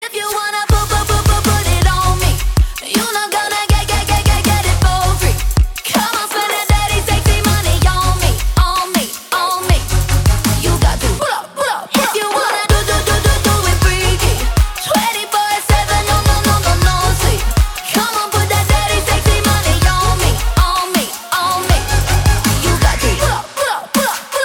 • Качество: 320, Stereo
громкие
женский вокал
Хип-хоп
зажигательные
dance
RnB